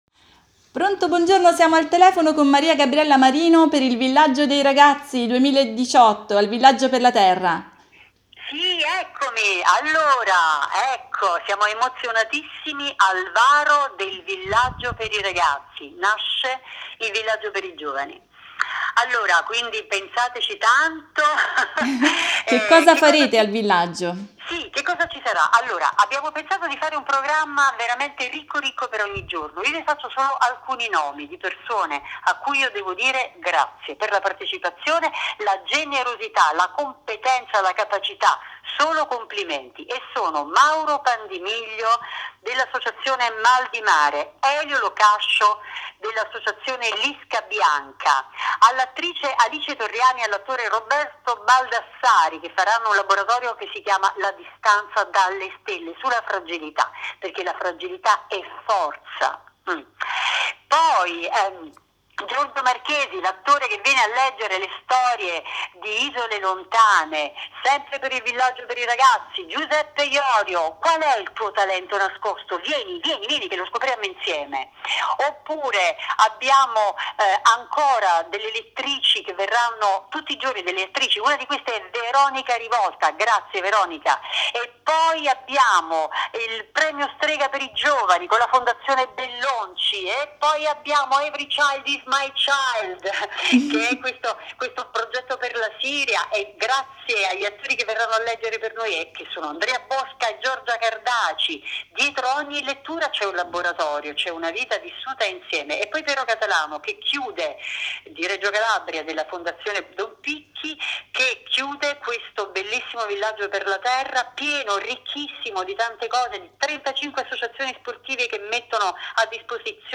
in questa intervista telefonica